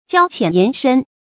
交淺言深 注音： ㄐㄧㄠ ㄑㄧㄢˇ ㄧㄢˊ ㄕㄣ 讀音讀法： 意思解釋： 跟交情淺的人談心里話。